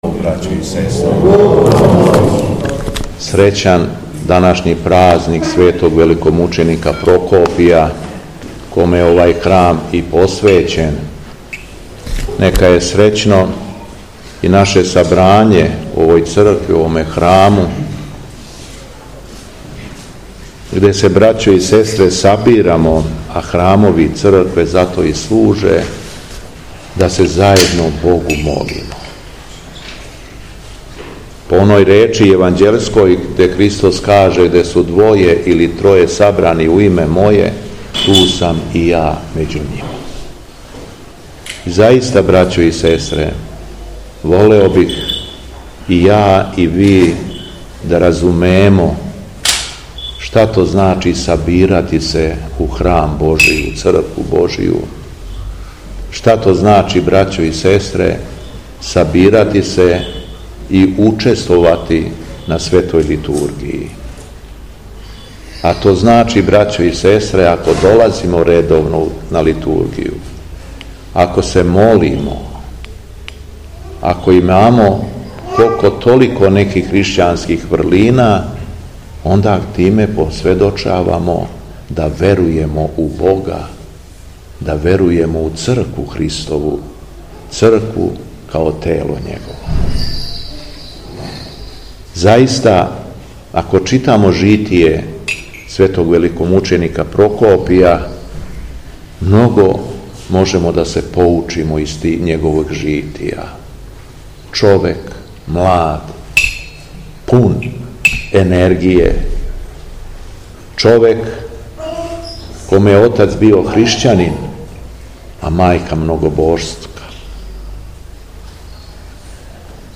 Беседа Његовог Високопреосвештенства Митрополита шумадијског г. Јована